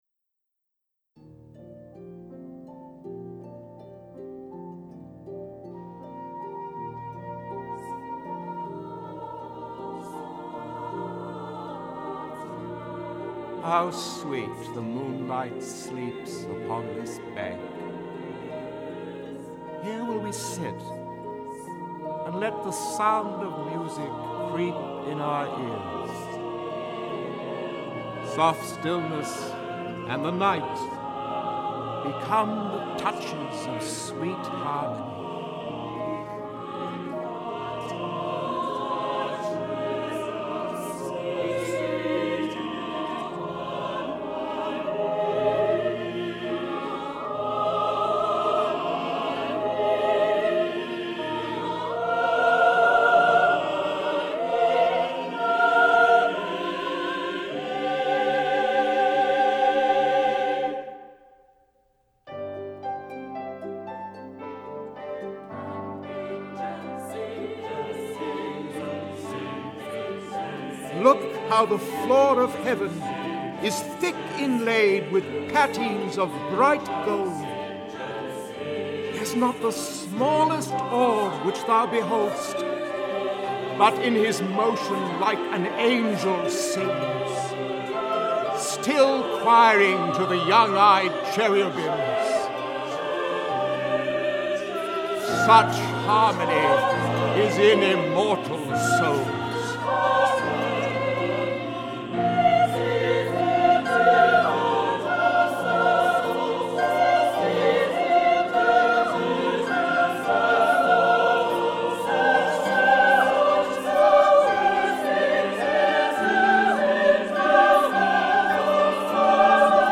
Voicing: Narrator, SATB